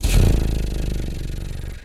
pgs/Assets/Audio/Animal_Impersonations/cat_2_purr_08.wav at master
cat_2_purr_08.wav